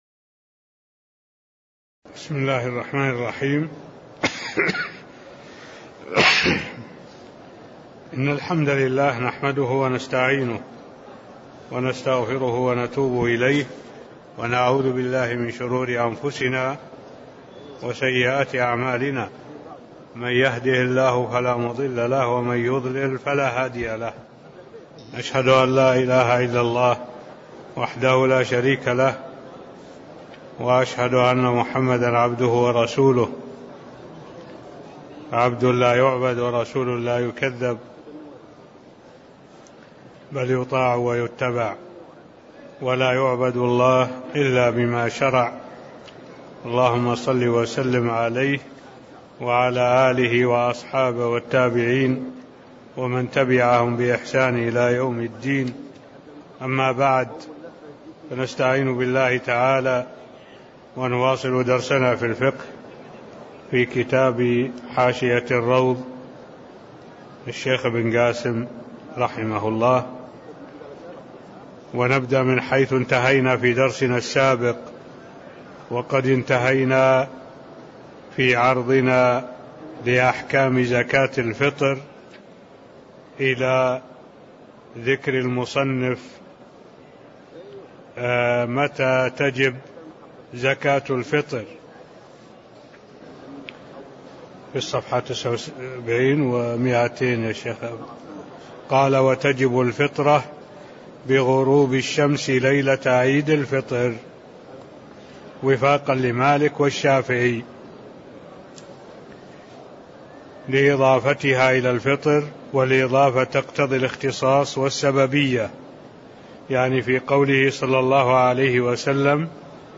تاريخ النشر ١٩ جمادى الأولى ١٤٢٩ هـ المكان: المسجد النبوي الشيخ: معالي الشيخ الدكتور صالح بن عبد الله العبود معالي الشيخ الدكتور صالح بن عبد الله العبود متى تجب زكاةالفطر (007)